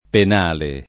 [ pen # le ]